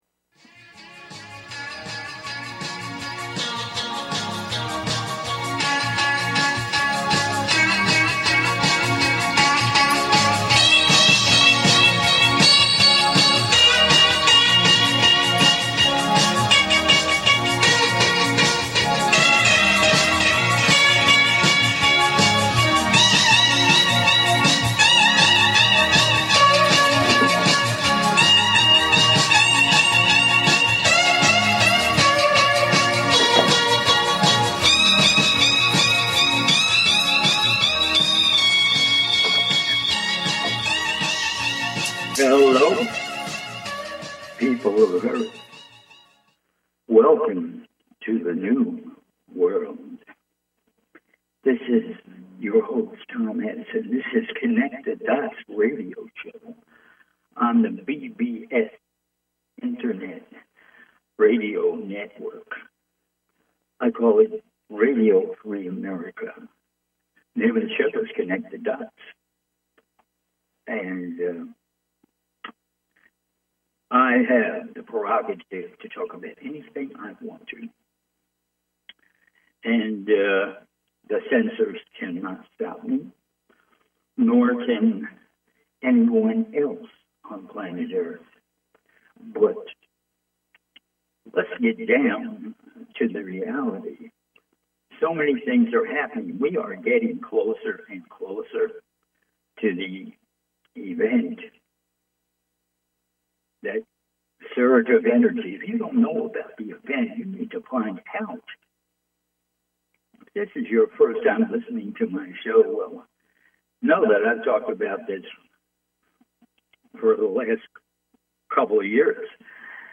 Talk Show Episode
call in radio talk show